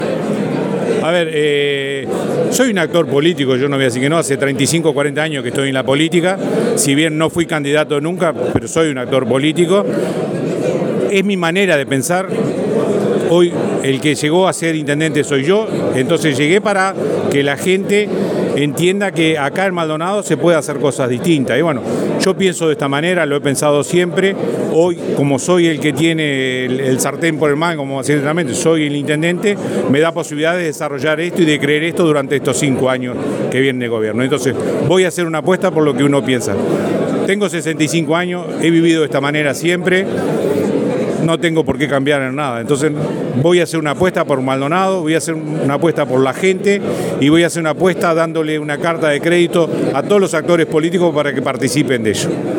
Intendente de Maldonado, Miguel Abella